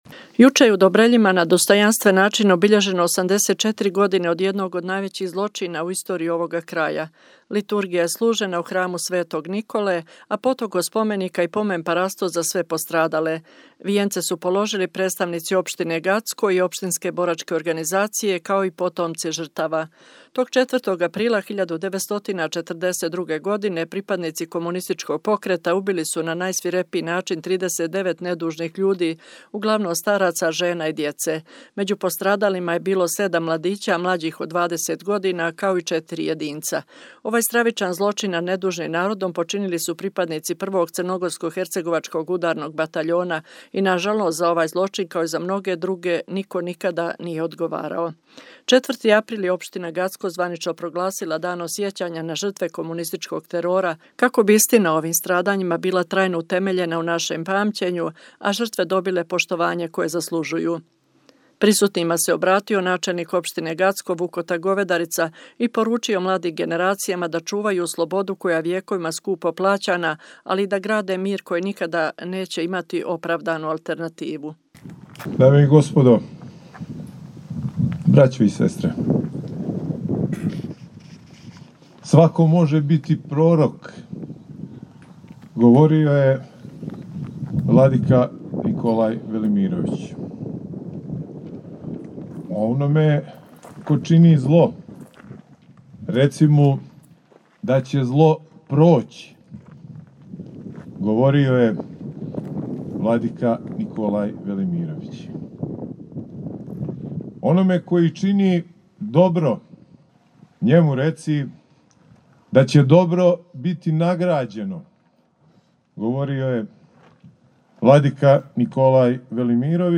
Juče je u Dobreljima, na dostojanstven način obilježeno 84 godine od jednog od najvećih zločina u istoriji ovoga kraja. Liturgija je služena u hramu Sv. Nikole a potom kod spomenika i pomen parastos za sve postradale.
Prisutnima se obratio načelnik opštine Gacko Vukota Govedrica i poručio mladim generacijama da čuvaju slobodu koja je vjekovima skupo plaćana ali i da grade mir koji nikada neće imati opravdanu alternativu.